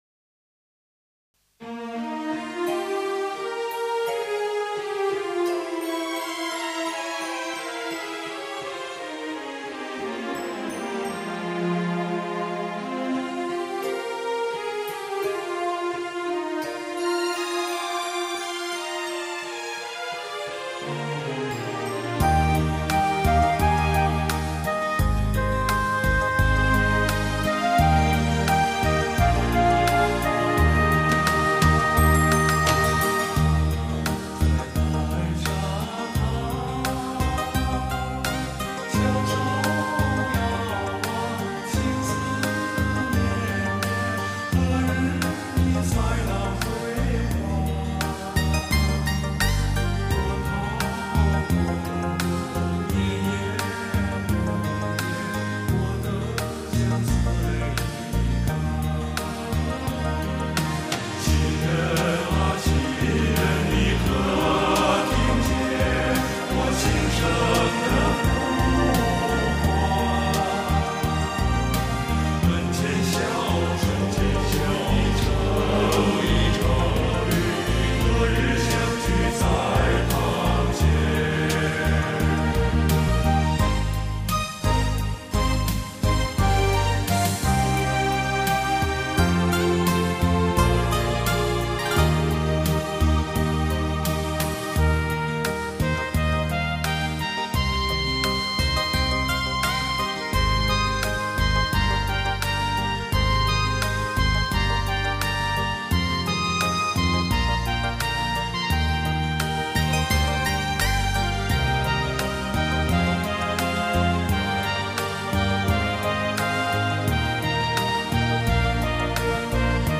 经典民歌魅力尽显，隽永精品深情演绎，承前启后，中西交融，亦庄亦谐，风格独具。
广东音乐